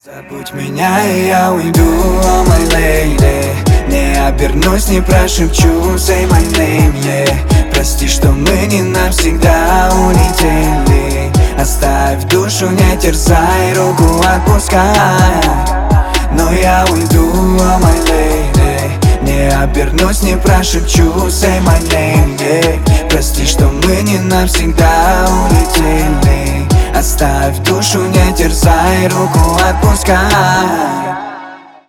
• Качество: 320 kbps, Stereo
Рэп и Хип Хоп
грустные